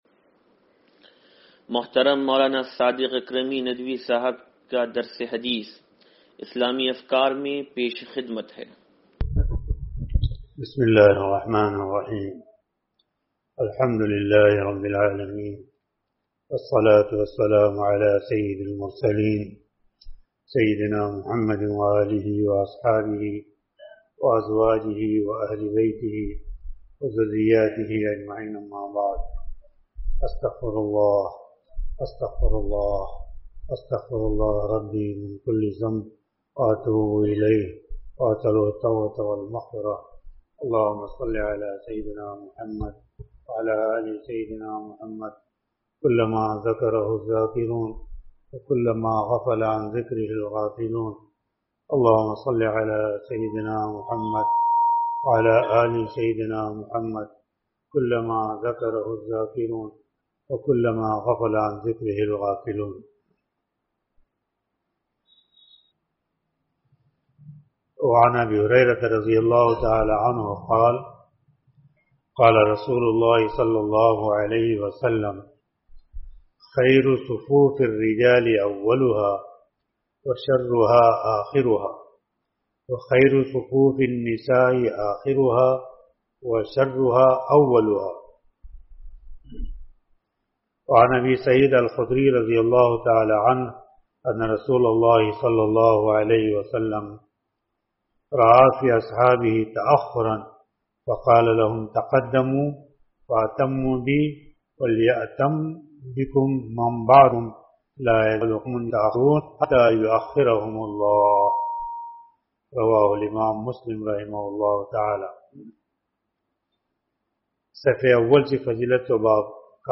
درس حدیث نمبر 0821